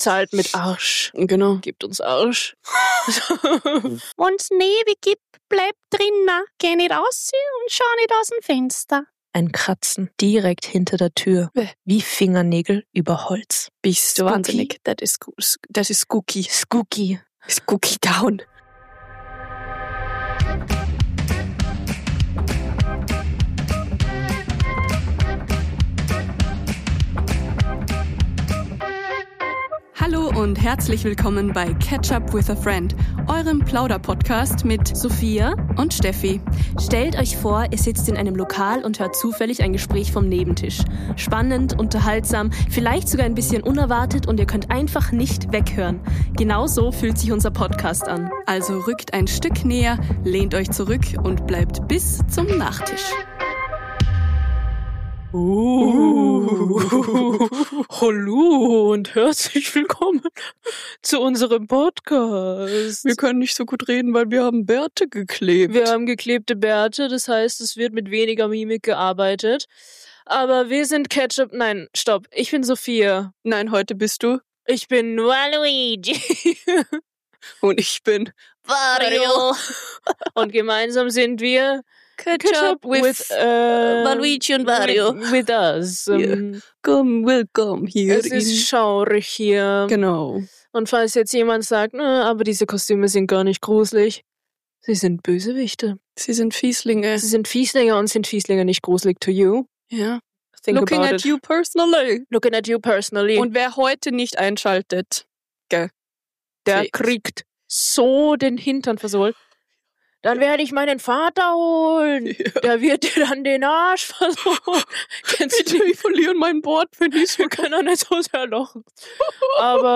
Ein finsterer Abend, 2 Schurken mit Mikrofon. Halloween im Studio: Wario und Waluigi lesen sich Gruselgeschichten vor - und schaffen es, selbst die Geister zum Lachen zu bringen.